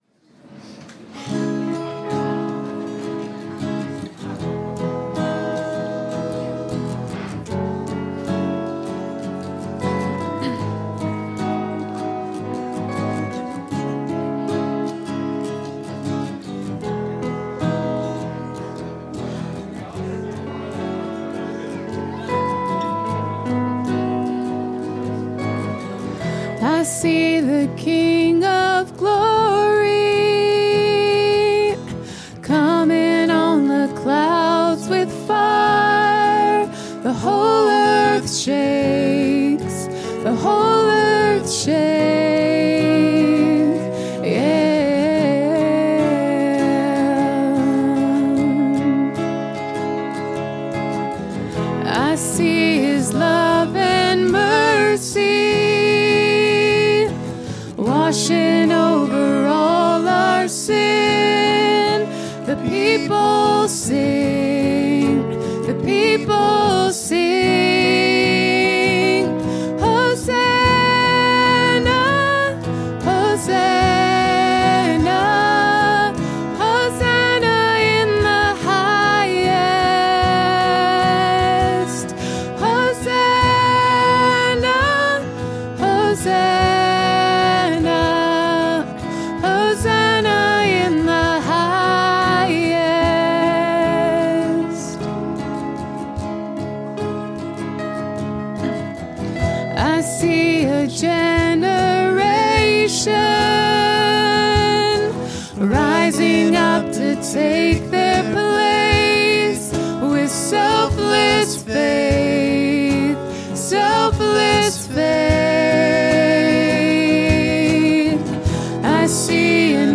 Worship 2025-04-13